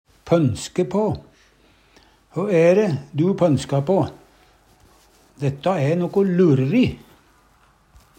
DIALEKTORD PÅ NORMERT NORSK pønske på tenkje ut, spekulere på Eksempel på bruk Hø æ ræ du pønska på?